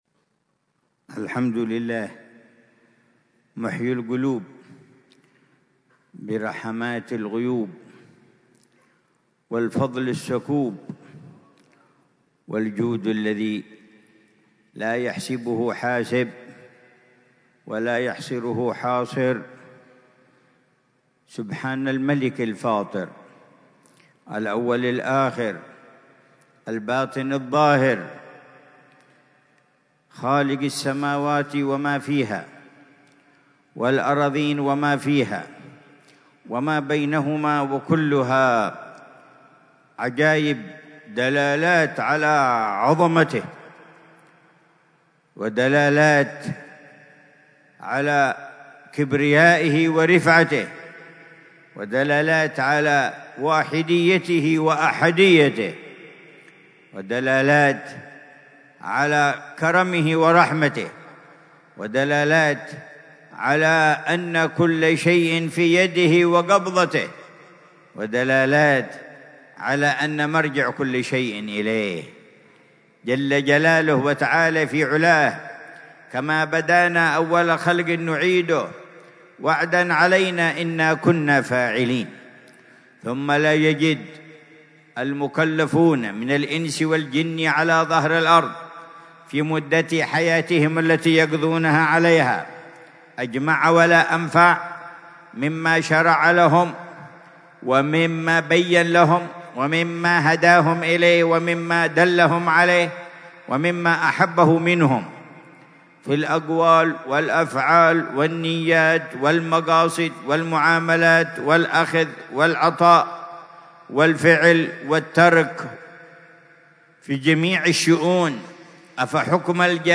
محاضرة العلامة الجبيب عمر بن محمد بن حفيظ ضمن سلسلة إرشادات السلوك، في دار المصطفى، ليلة الجمعة 27 شوال 1446هـ بعنوان: